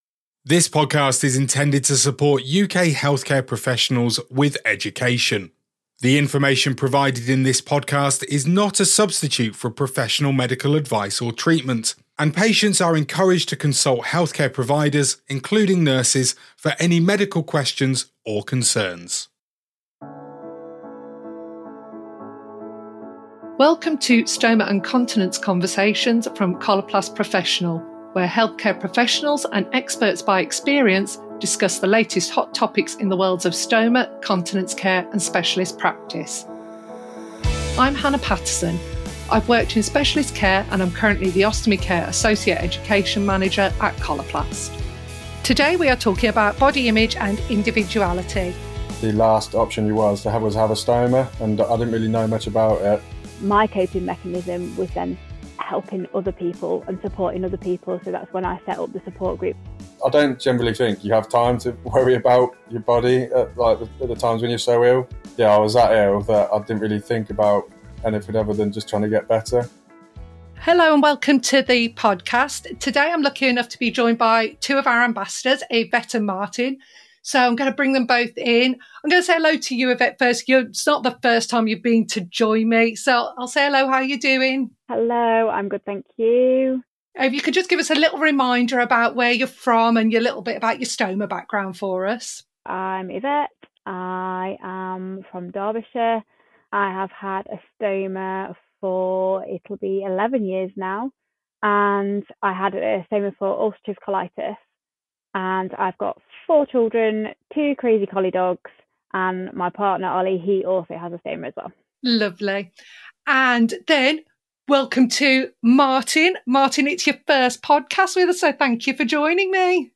In some great and interesting conversations, they discuss what body image with a stoma means to them and others and the importance of maintaining their individuality.